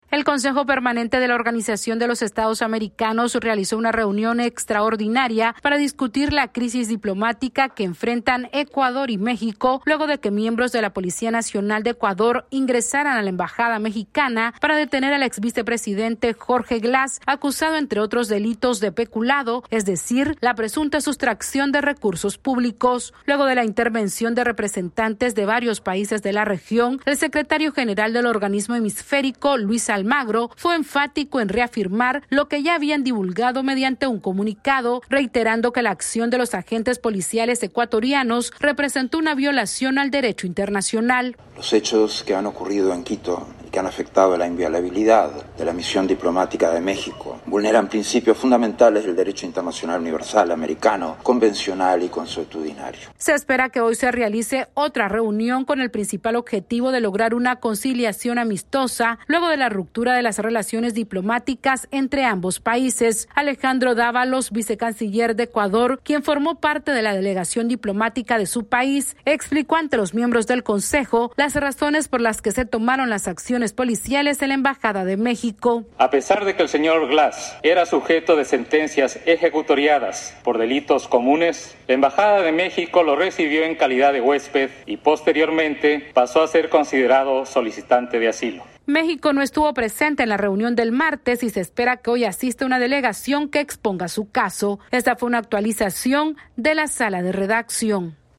AudioNoticias
La OEA intenta lograr una conciliación entre los gobiernos de México y Ecuador luego del rompimiento de relaciones diplomáticas. Esta es una actualización de nuestra Sala de Redacción...